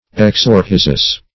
Search Result for " exorhizous" : The Collaborative International Dictionary of English v.0.48: Exorhizal \Ex`o*rhi"zal\, Exorhizous \Ex`o*rhi`zous\, a. (Bot.)
exorhizous.mp3